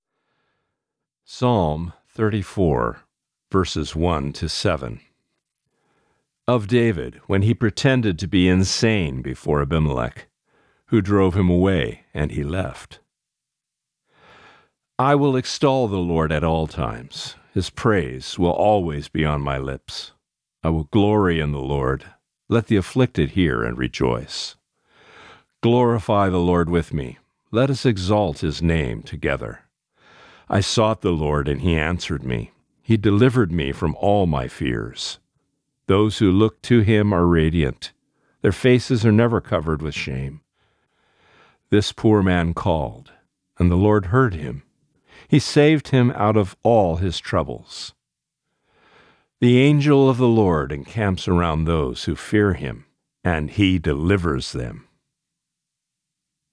Reading: Psalm 34:1-7